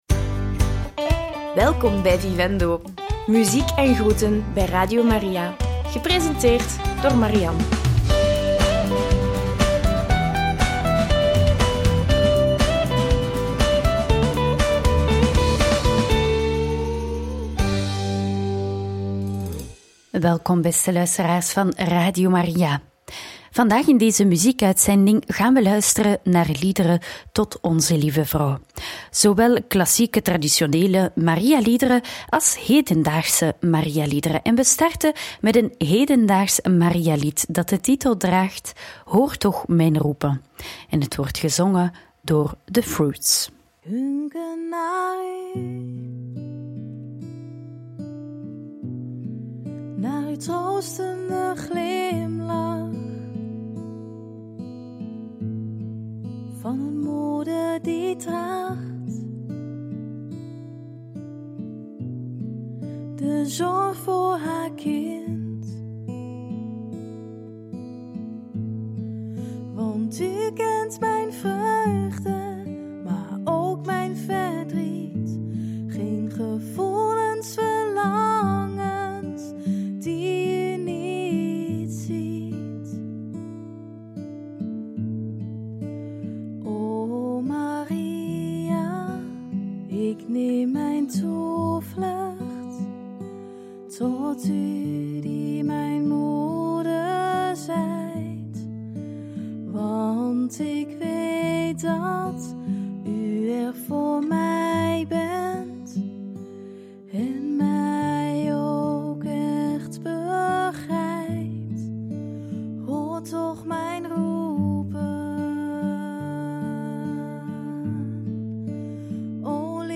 Traditionele en hedendaagse liederen tot Onze-Lieve-Vrouw – Radio Maria
traditionele-en-hedendaagse-liederen-tot-onze-lieve-vrouw.mp3